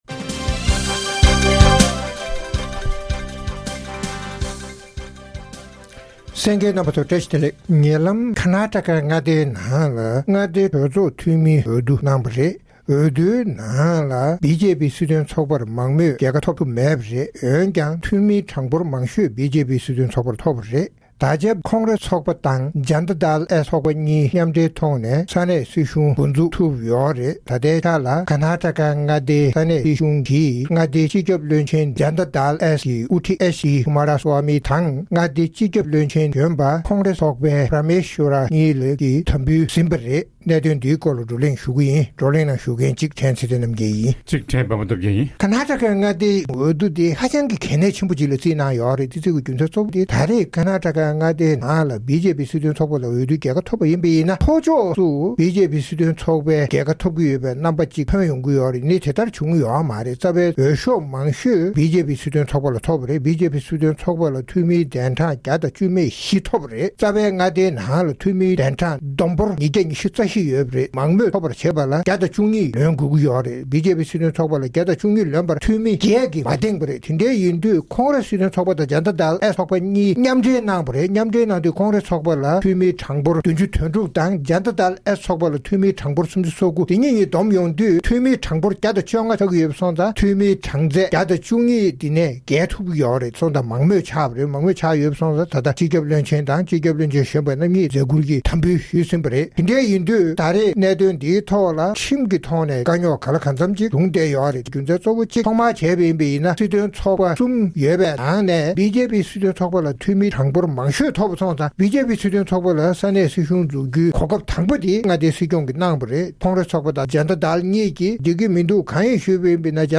༄༅༎ཐེངས་འདིའི་རྩོམ་སྒྲིག་པའི་གླེང་སྟེགས་ཞེས་པའི་ལེ་ཚན་ནང་། ཉེ་ལམ་རྒྱ་གར་ལྷོ་ཕྱོགས་ཀྱི་མངའ་སྡེ་Karnataka འི་ནང་མངའ་སྡེའི་གྲོས་ཚོགས་འཐུས་མིའི་འོས་བསྡུ་གནང་སྟེ་Janta Dal Secular དང་། Congress སྲིད་དོན་ཚོགས་པ་གཉིས་མཉམ་འདུས་ཀྱི་མངའ་སྡེའི་སྲིད་གཞུང་འཛུགས་ཐུབ་ཡོད་པ་མ་ཟད། འབྱུང་འགྱུར་རྒྱལ་ཡོངས་འོས་བསྡུའི་ནང་ཕྱོགས་འགལ་ཚོགས་པ་རྣམས་མཉམ་འདུས་ཐུབ་པའི་རེ་བ་ཡོད་པ་བཅས་ཀྱི་སྐོར་རྩོམ་སྒྲིག་འགན་འཛིན་རྣམ་པས་བགྲོ་གླེང་གནང་བ་ཞིག་གསན་རོགས་གནང་།།